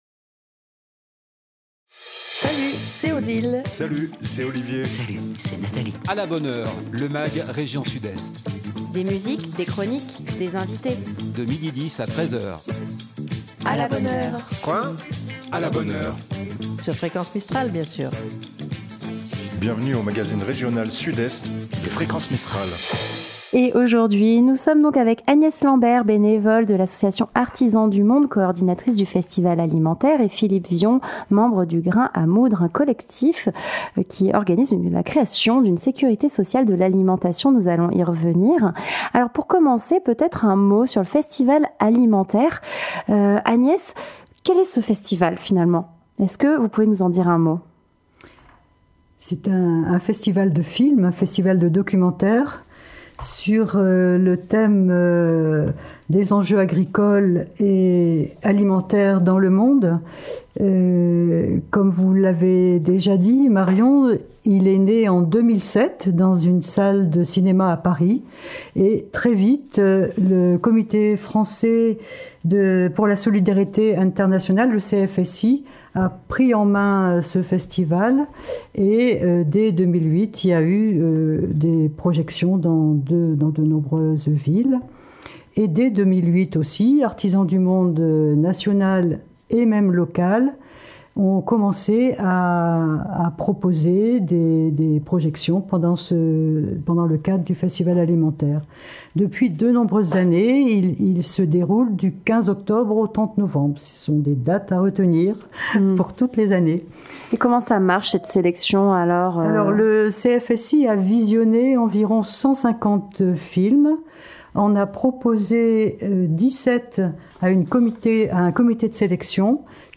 " Bienvenue dans le magazine région Sud-Est de Fréquence Mistral !
De Marseille à Briançon en passant par Manosque, sans oublier Dignes les bains, et Gap, un magazine régional, un Mag rien que pour vous, des invité.e.s en direct, des chroniques musique, cinéma, humour, littéraire, sorties et sur divers thèmes qui font l’actualité. Aujourd'hui nous nous interessons au festival Alimenterre, évènement international sur l’alimentation durable et solidaire organisé chaque année du 15 octobre au 30 novembre.